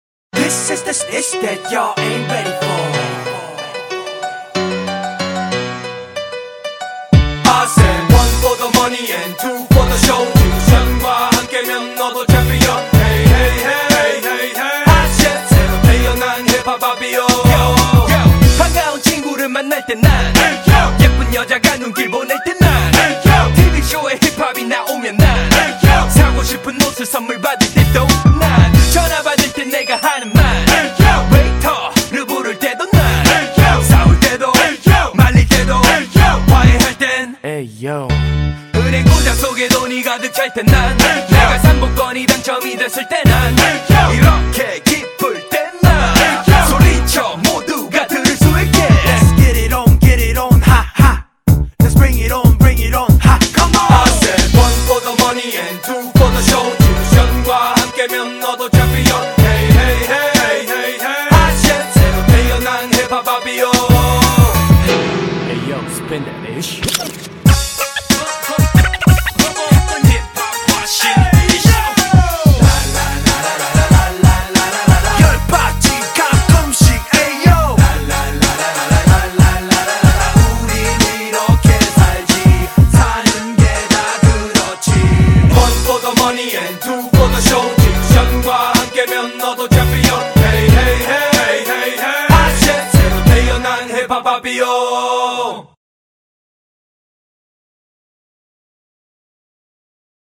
BPM93--1
Audio QualityPerfect (High Quality)